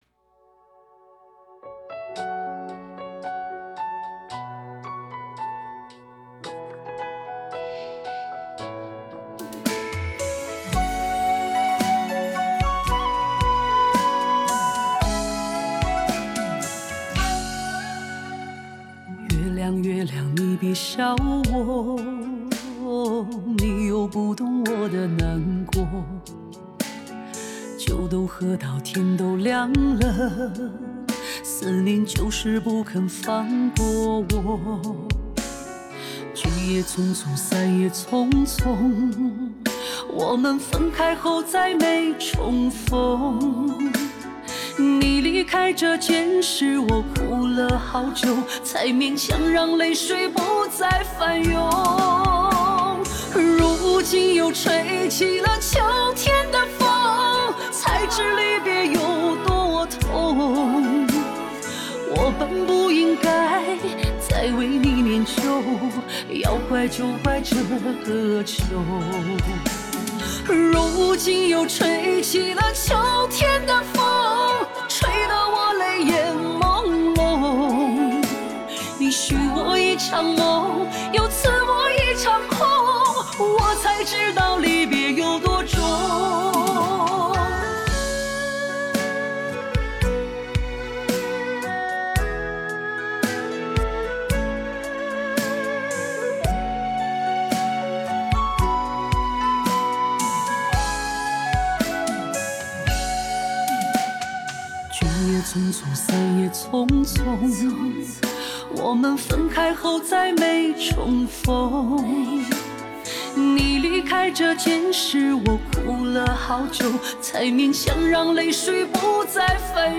无损音乐，音质一流